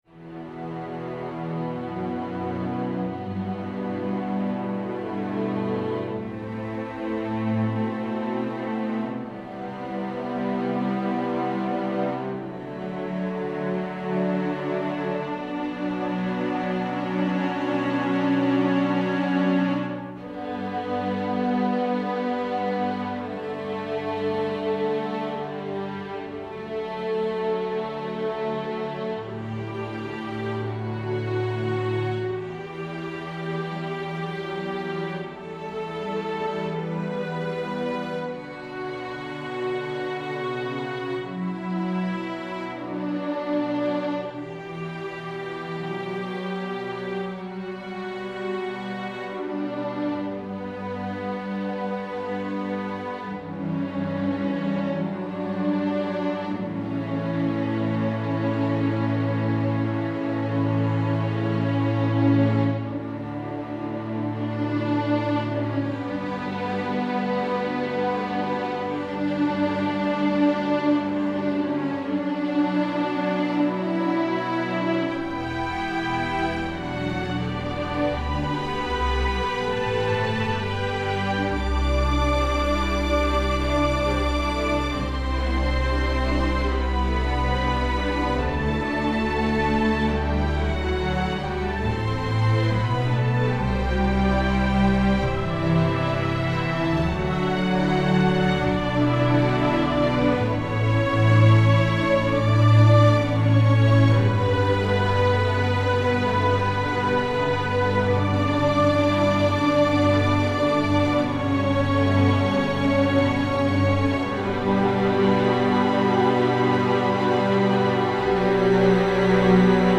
adapted for developing school orchestras